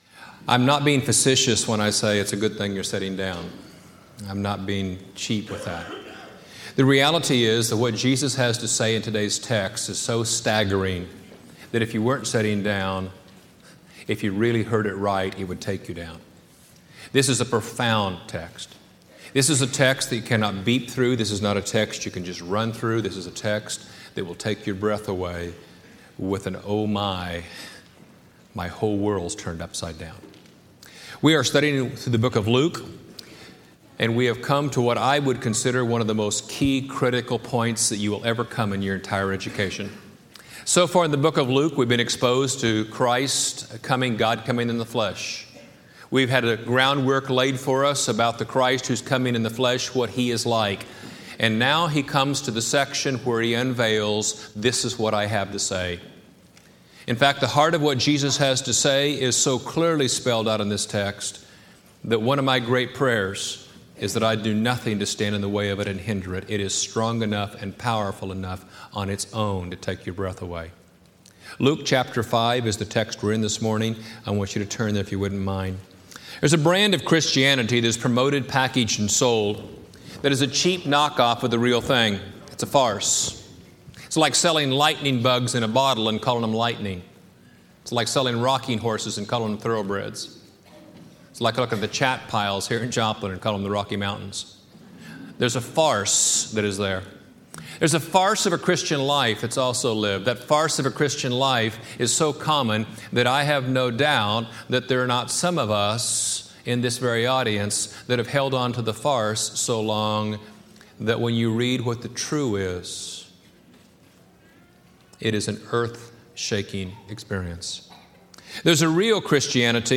The Real & Radical Christian Life Preached at College Heights Christian Church January 15, 2006 Series: Luke, 2006 Scripture: Luke 5-6 Audio